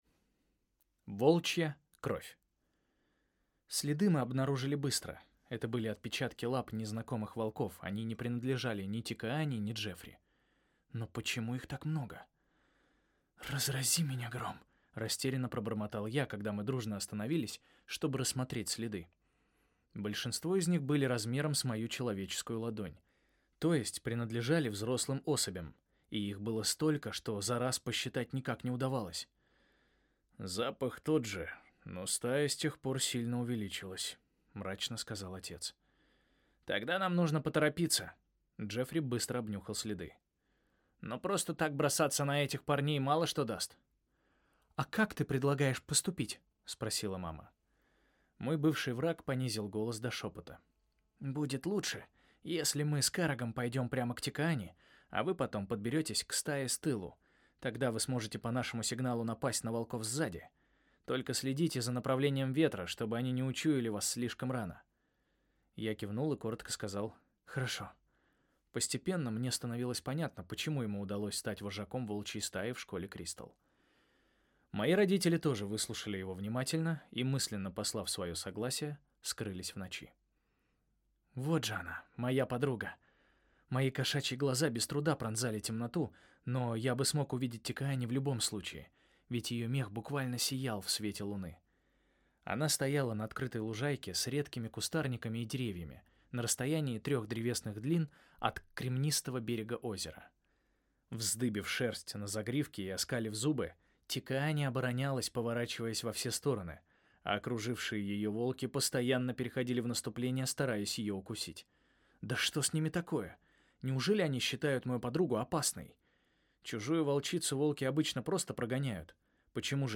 Аудиокнига Караг и волчье испытание | Библиотека аудиокниг